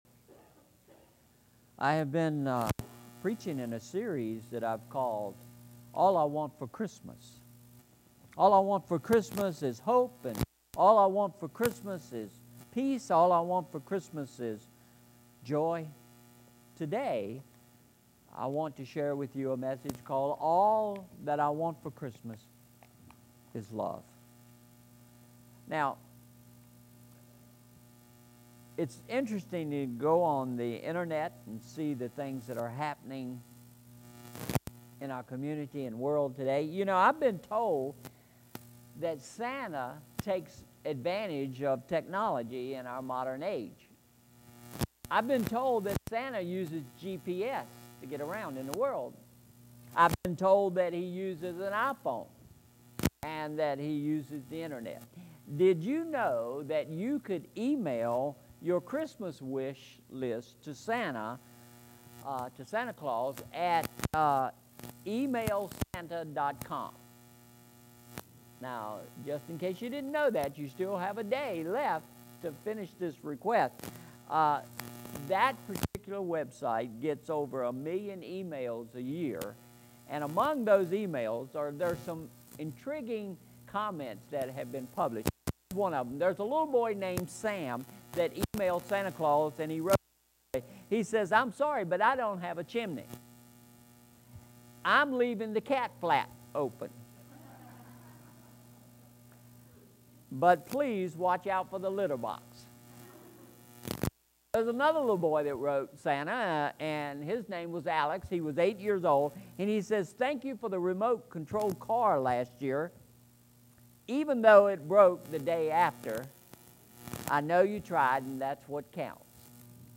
Message Aim: Fourth Sunday of Advent